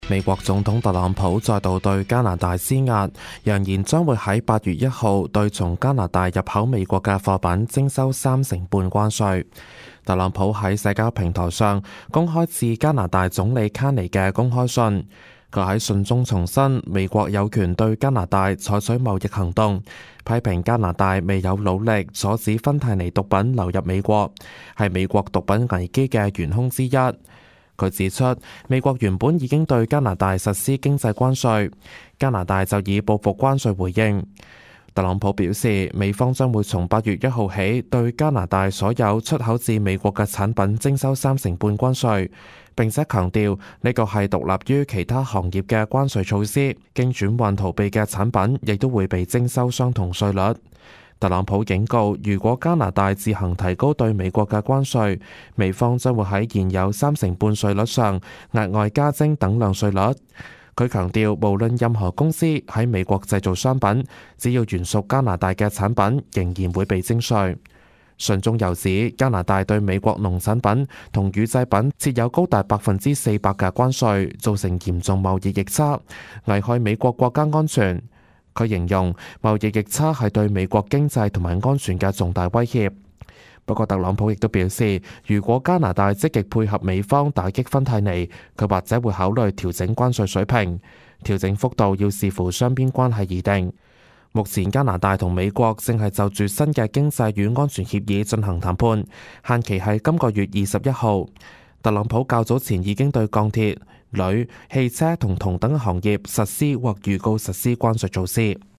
news_clip_23900.mp3